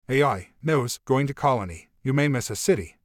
この句の心が聞こえてくると思います。(伝統的な空耳という技法です。)